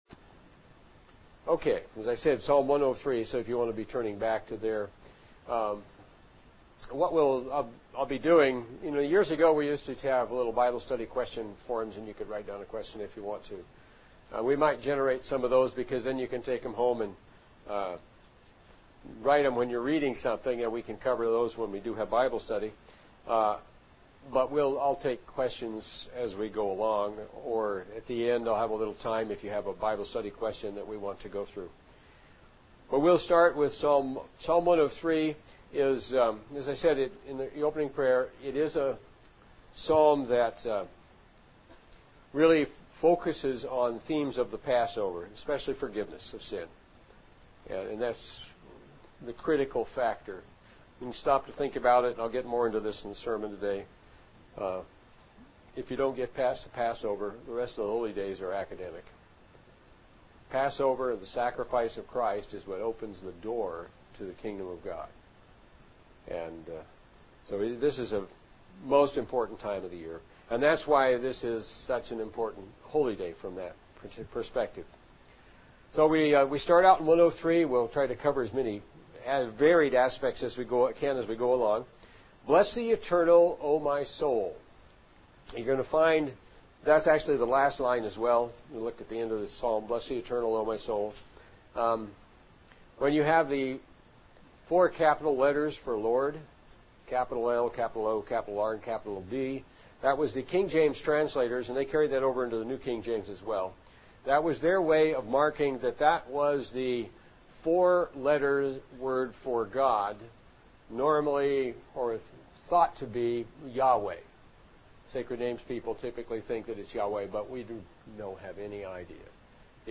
UCG Sermon Studying the bible?
Given in North Canton, OH